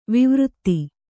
♪ vivřtti